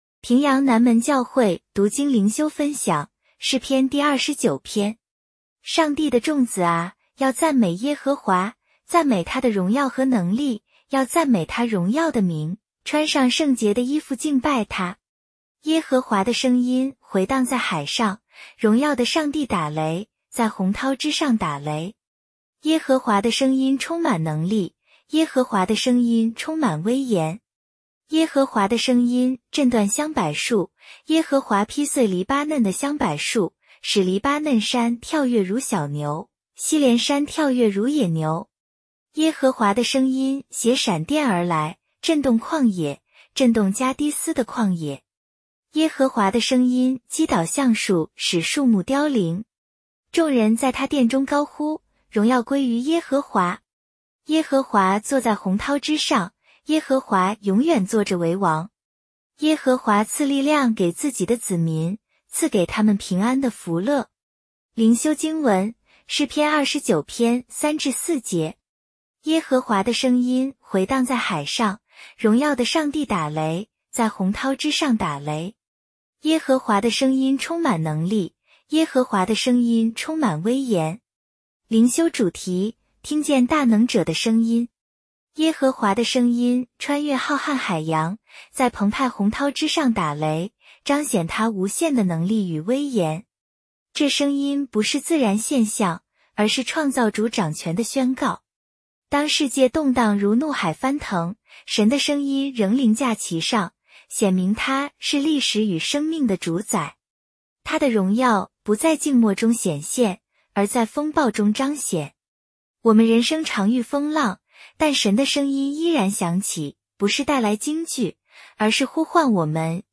普通话朗读——诗29